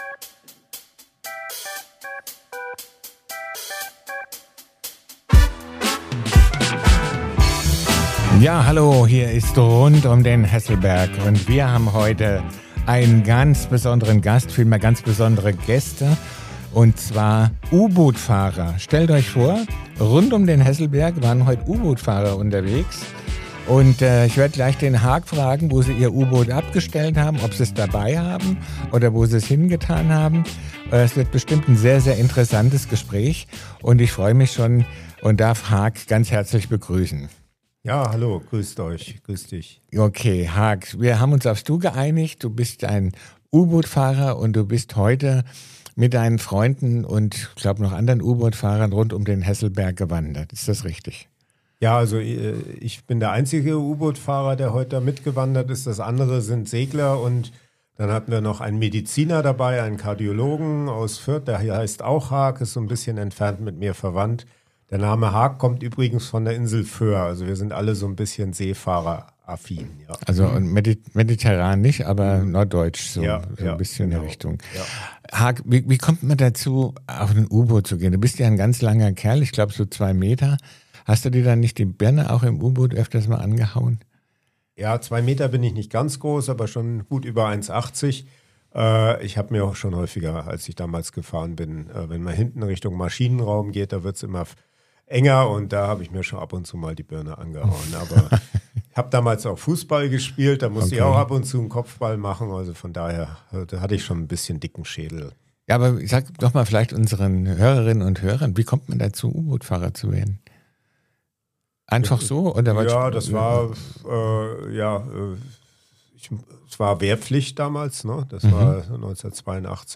In dieser Folge spreche ich mit einem echten U-Bootfahrer, der von seinen einzigartigen Erlebnissen unter Wasser berichtet.